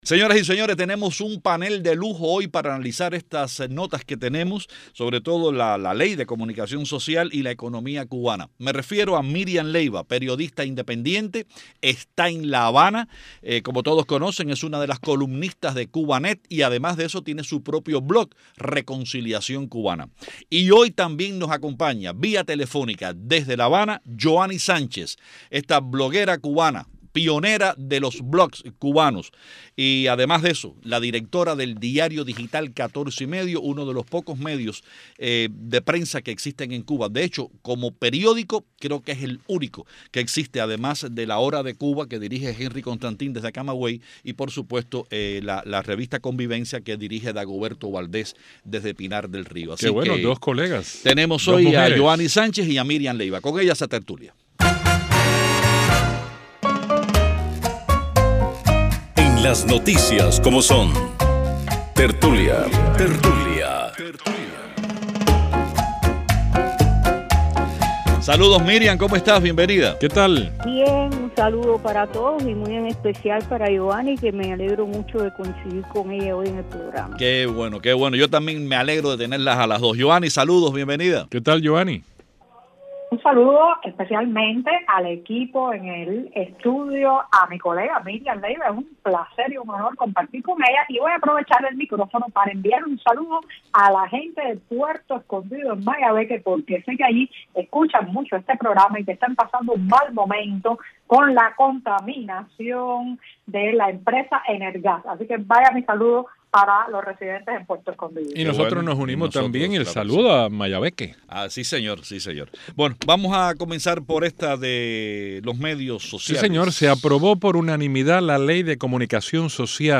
Fragmento de la entrevista